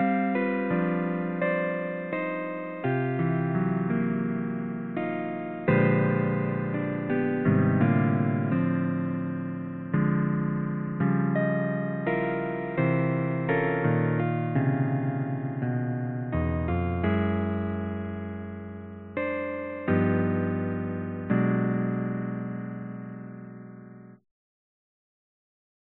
标签： 爵士乐 midi 钢琴
声道立体声